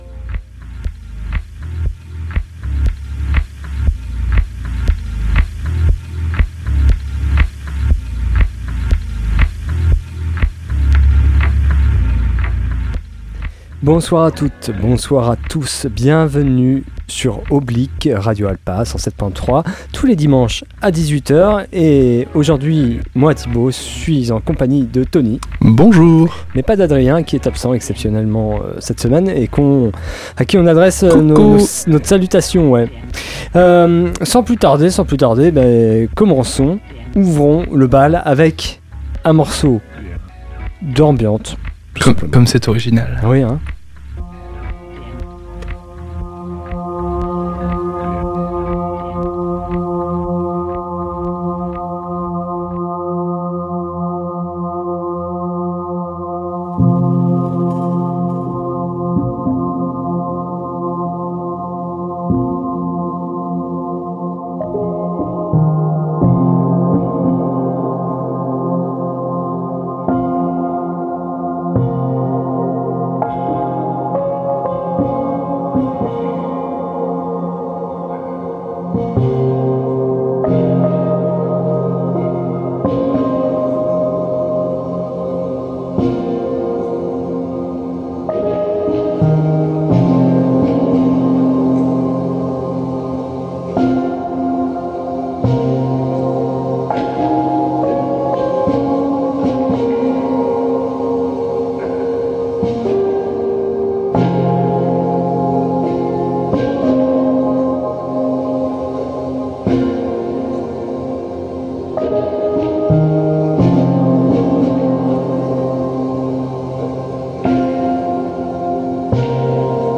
ELECTRONICA